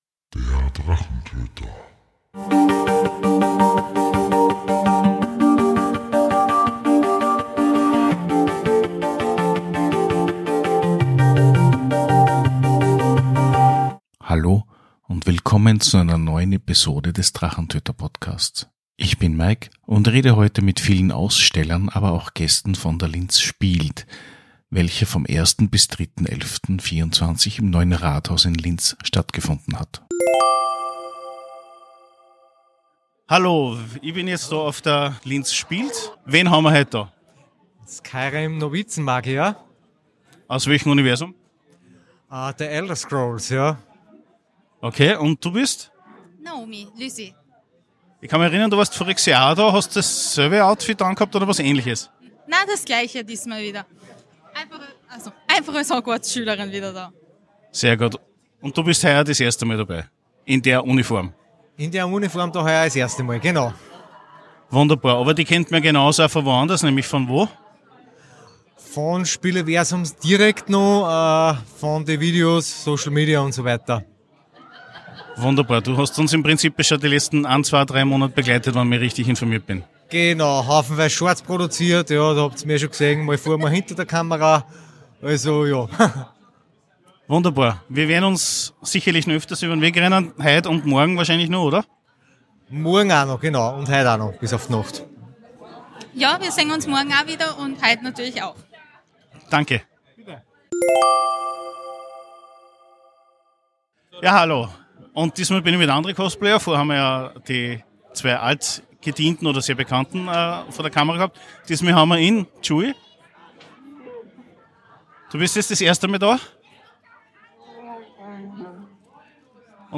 Episode 149 - Spieleversum - Linz Spielt! 2024 - Die Interviews ~ Der Drachentöter Podcast